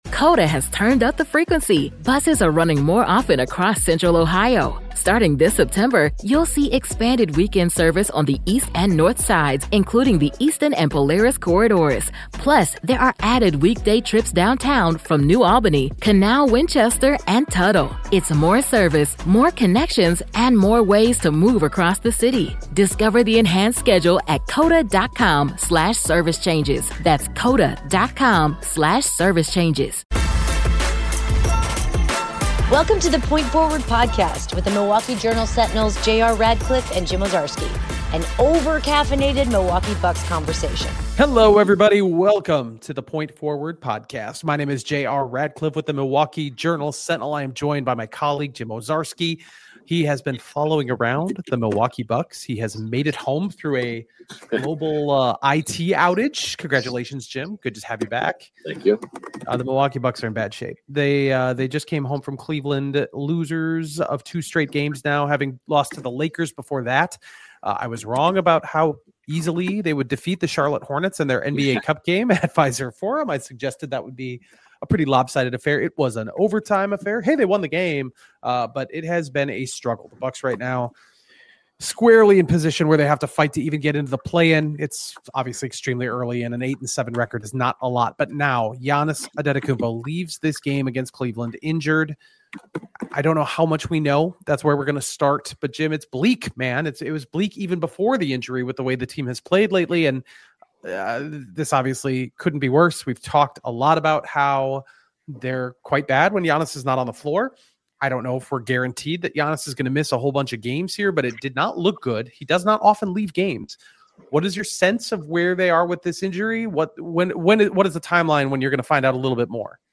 How much trouble are the Bucks in? Even if Giannis comes back quickly, who else needs to step up to get the Bucks back on a clear playoff trajectory? Music intro